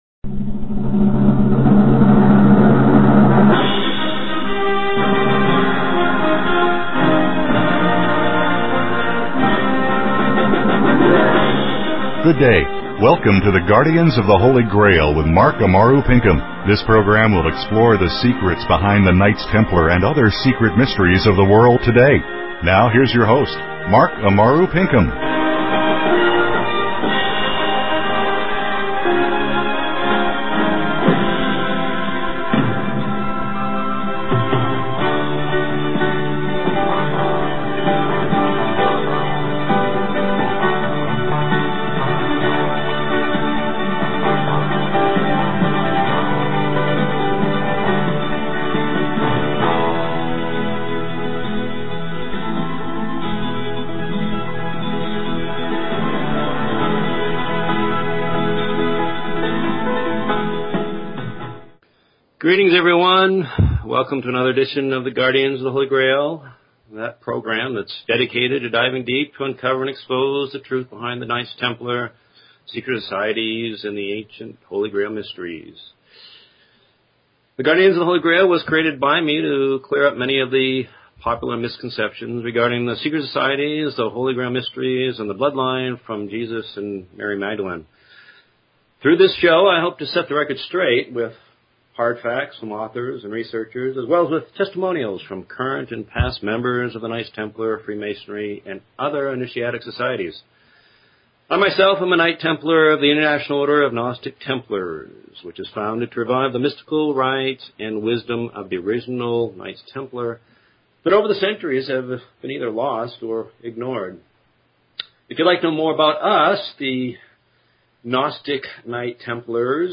Talk Show Episode, Audio Podcast, The_Guardians_of_the_Holy_Grail and Courtesy of BBS Radio on , show guests , about , categorized as